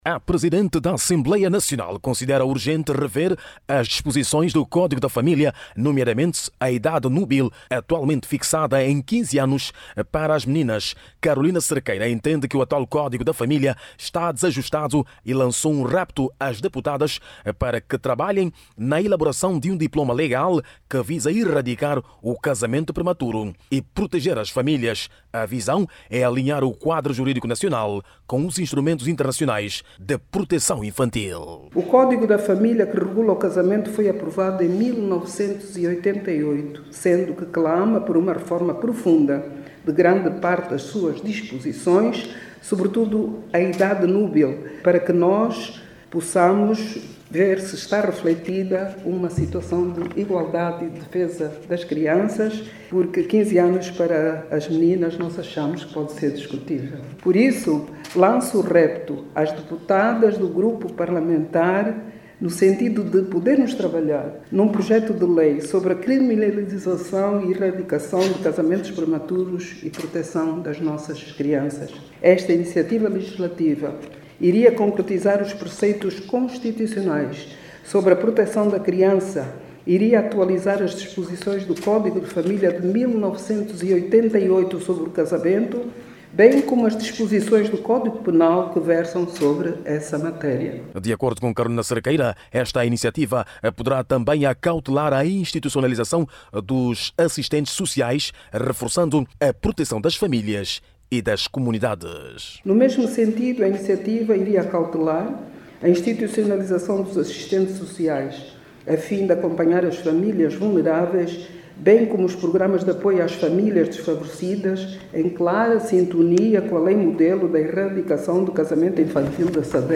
Durante a reunião ordinária do Grupo de Mulheres Parlamentares, Carolina Cerqueira defendeu hoje a criminalização dos casamentos prematuros, sublinhando que esta prática continua a comprometer seriamente o futuro de muitas meninas em Angola.